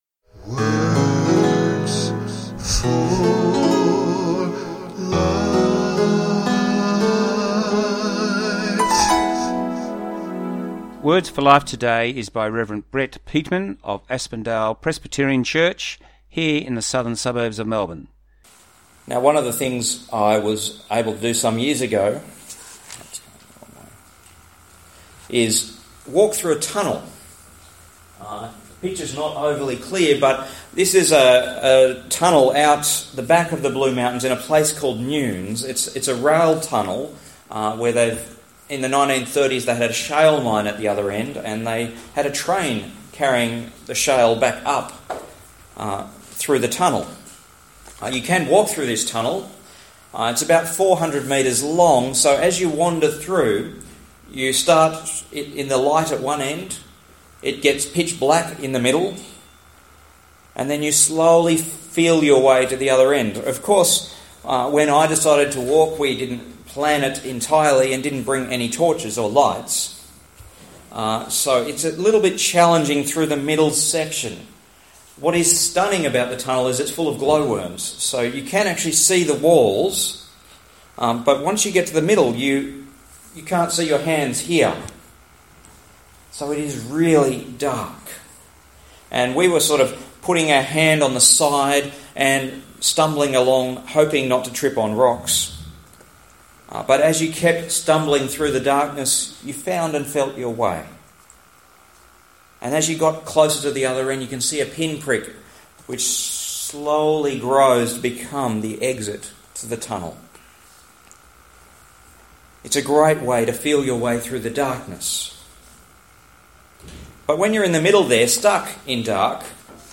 This was broadcast on 14Jan18 on Songs of Hope. Talk time is 22 minutes Press play to listen or download.